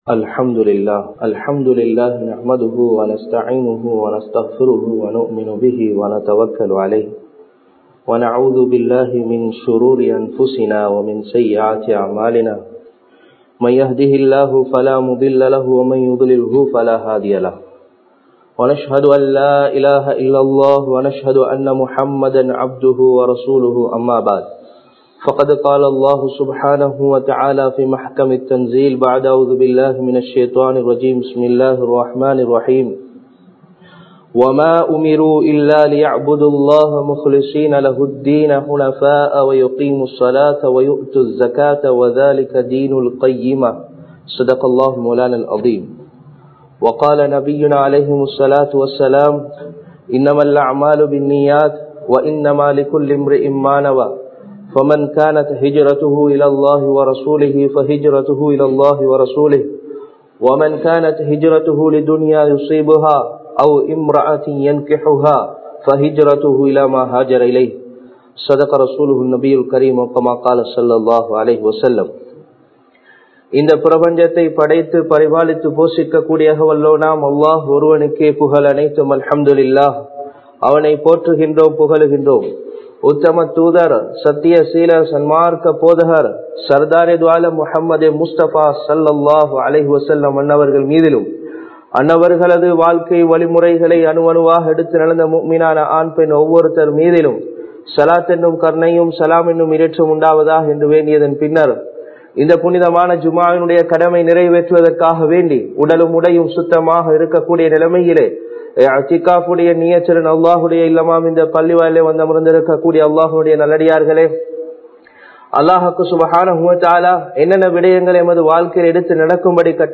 ரூஹ் என்றால் என்ன? (What is The Soul?) | Audio Bayans | All Ceylon Muslim Youth Community | Addalaichenai